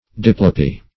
Diplopia \Di*plo"pi*a\, Diplopy \Dip"lo*py\, n. [NL. diplopia,